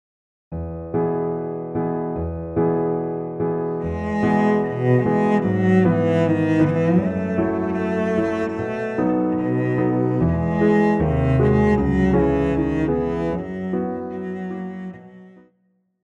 Classical
Cello
Piano
Instrumental
Solo with accompaniment
Here we have an arrangement for cello and piano.